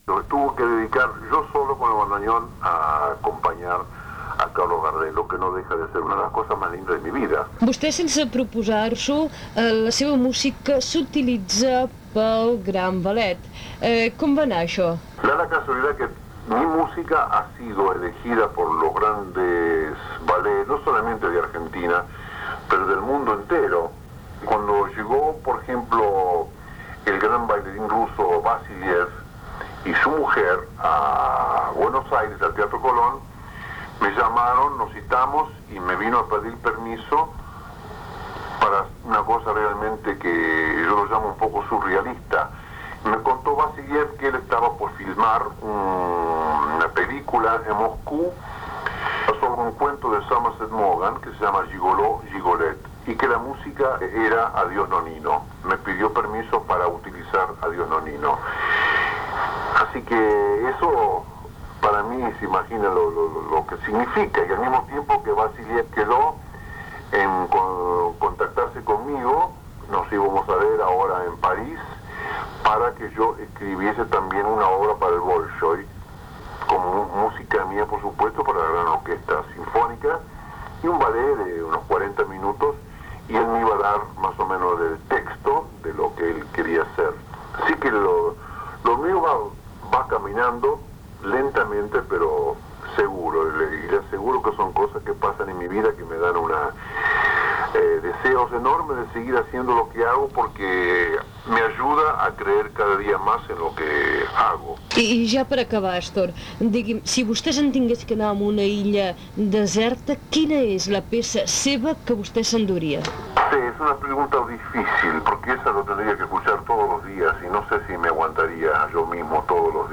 Espai dedicat al tango amb una entrevista al músic i compositor Astor Piazzola , publicitat, hora, temperatura.
Info-entreteniment
FM